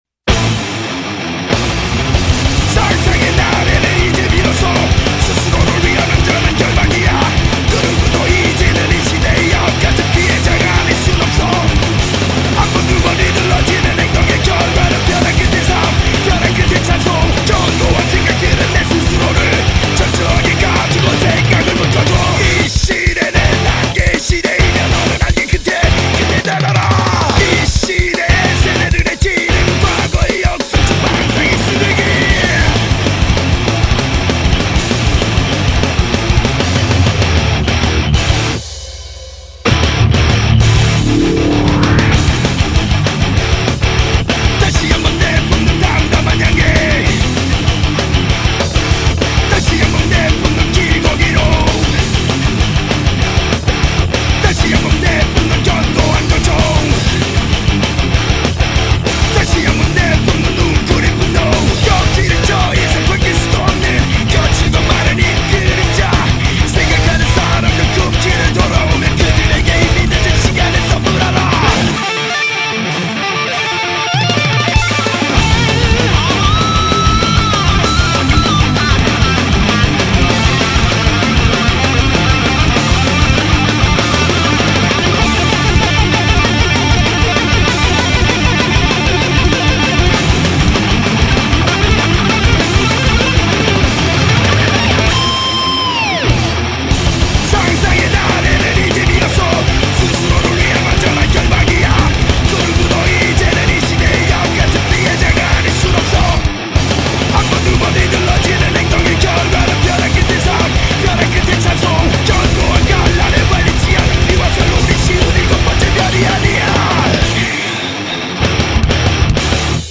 BPM195
Audio QualityPerfect (High Quality)
Comments[K-THRASH METAL]